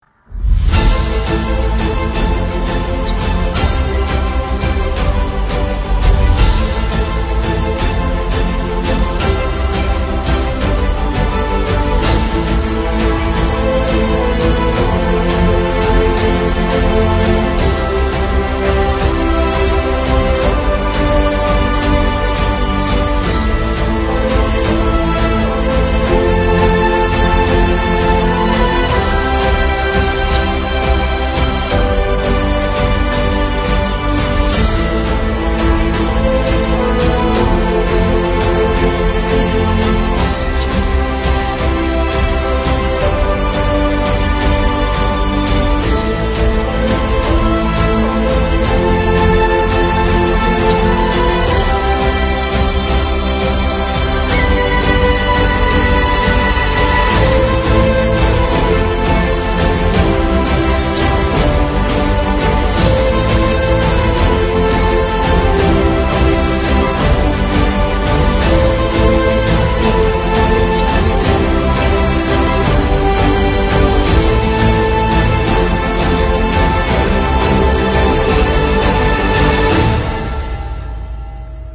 交响乐团双管编制，共由60名演奏员组成
演出还汇集了60余名合唱团员
著名指挥家李方方
全总文工团常务副团长兼艺术总监、著名女高音歌唱家、一级演员陈思思
音乐会以宏大壮美的管弦乐作品《红旗颂》开场，分“奋进新征程”和“建功新时代”上、下两个篇章，时长约100分钟，不仅荟萃了许多经典乐曲、脍炙人口的歌曲，而且还展示了诸多原创作品，如：歌唱祖国礼赞党的声乐作品《逐梦圆舞曲》《美丽中国梦》《我的绿水青山》《灯火里的中国》《举杯吧朋友》；大气磅礴的交响合唱作品《咱们工人有力量》《祖国赞美诗》；献礼北京冬奥的歌曲《雪花请柬》；颇具国韵色彩的民族器乐作品《中华气韵》（改编曲牌《夜深沉》）；阿卡贝拉男声组合作品《如果我有一双美丽的翅膀》；小提琴经典作品《查尔达什舞曲》以及大型管弦乐作品《欢庆舞曲》，整场音乐会由一曲豪迈铿锵的《亿万职工心向党》画上圆满句号。